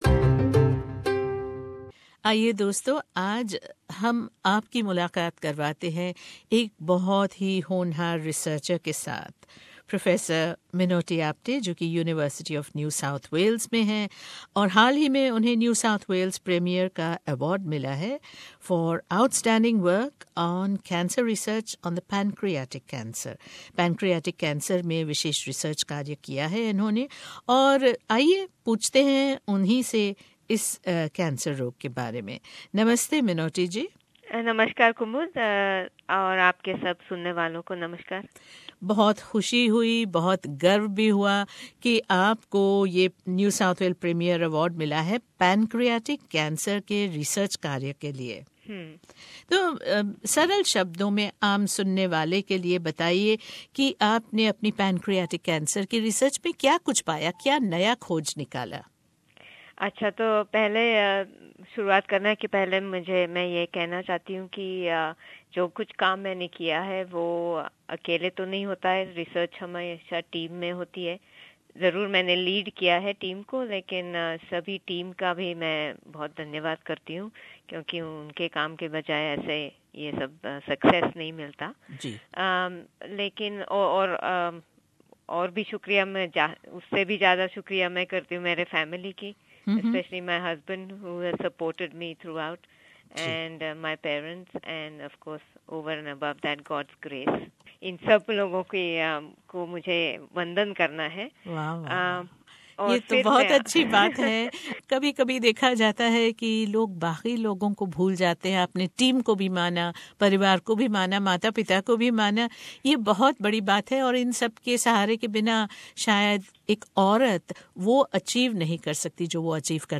एक्सक्लूसिव भेंटवार्ता।